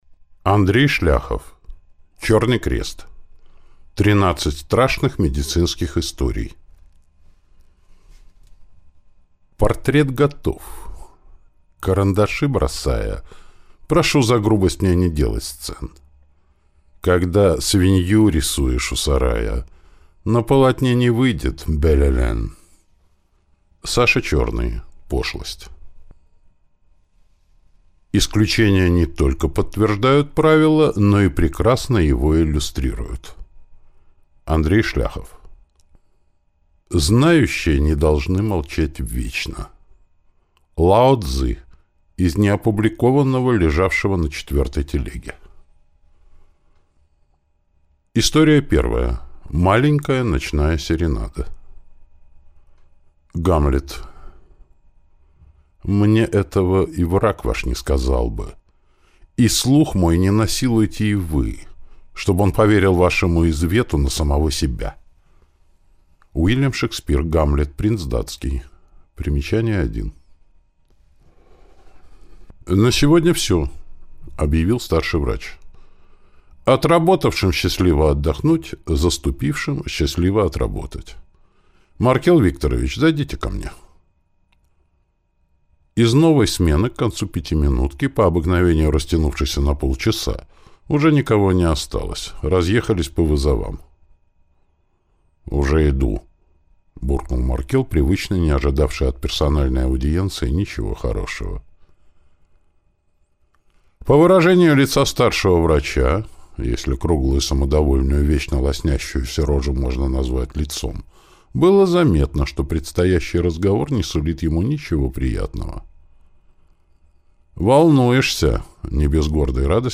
Аудиокнига Черный крест. 13 страшных медицинских историй - купить, скачать и слушать онлайн | КнигоПоиск